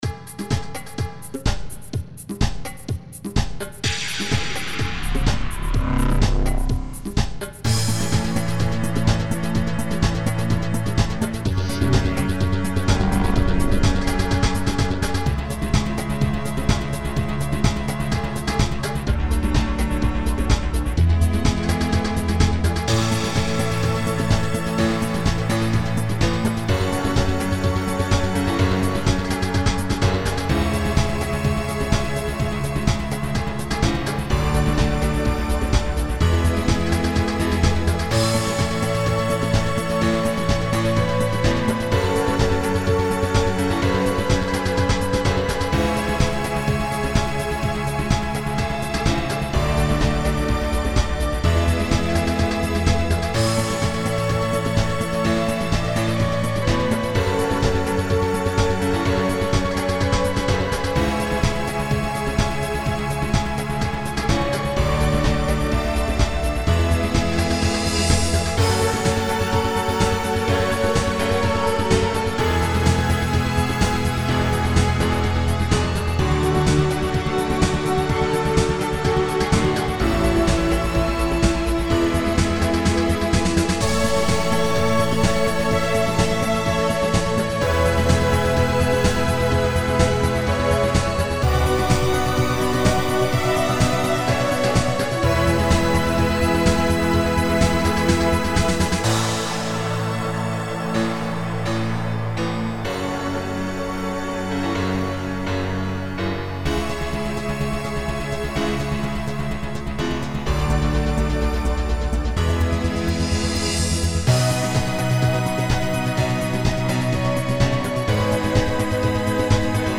Easy-listening